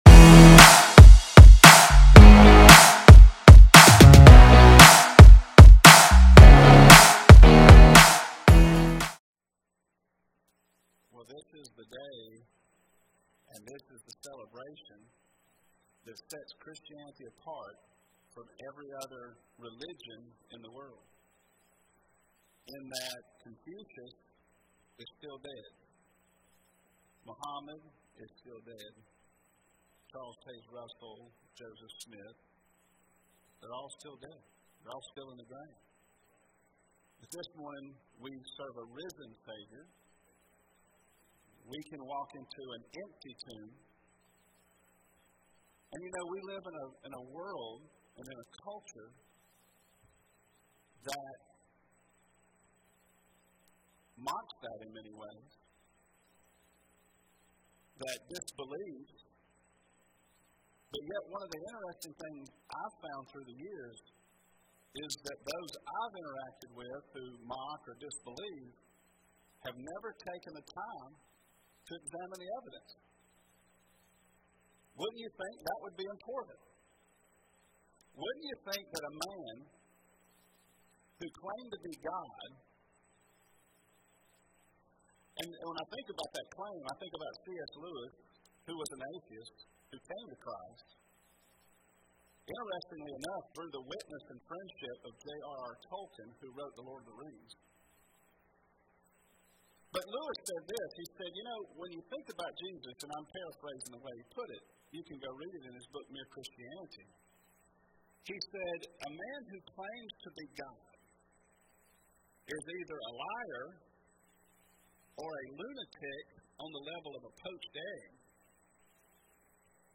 Sermons | More 2 Life Ministries
Join us for Easter Sunday and part 4 of our series: Anchored In Christ!